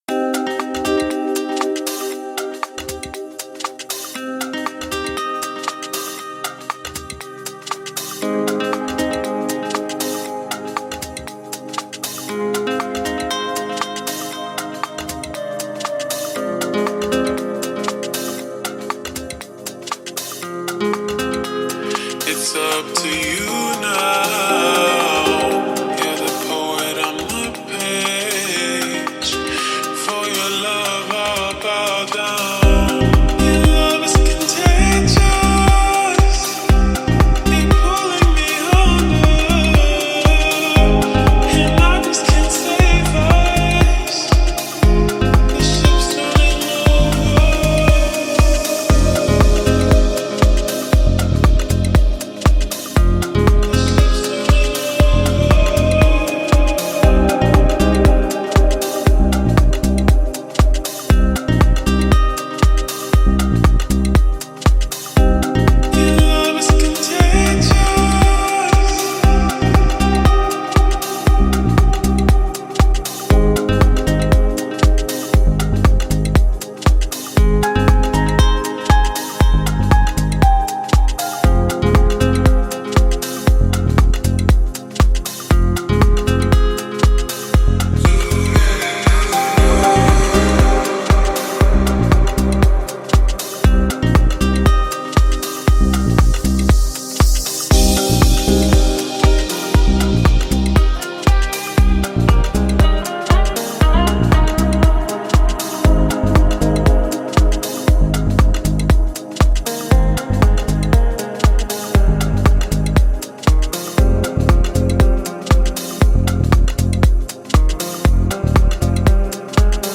это энергичная композиция в жанре EDM